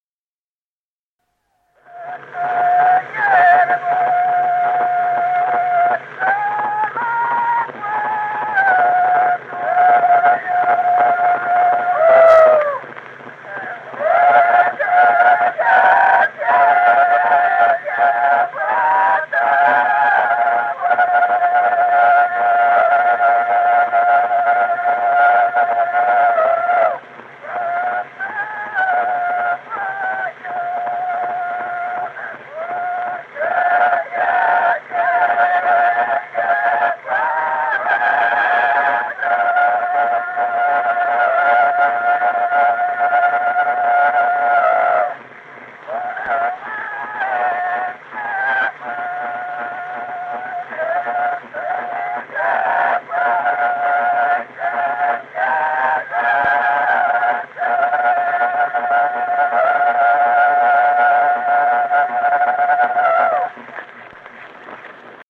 Песни села Остроглядово. Не стой, вербочка.